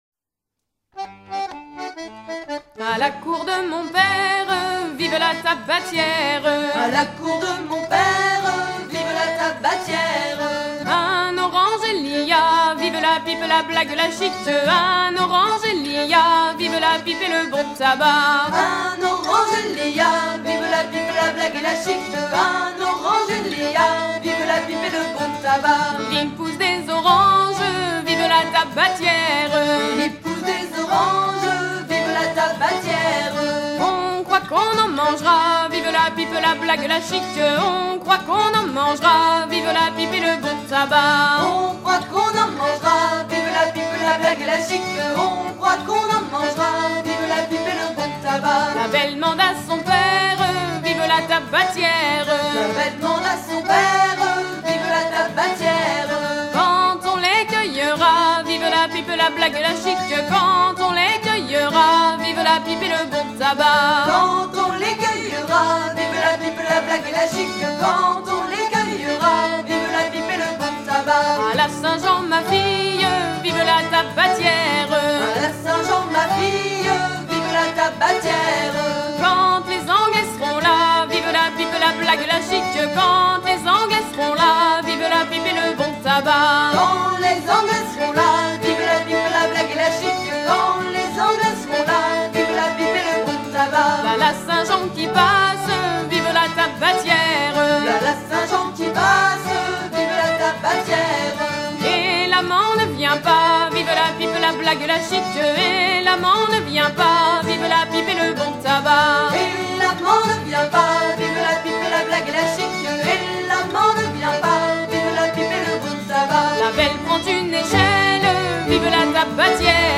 à virer au cabestan
Genre laisse
Pièce musicale éditée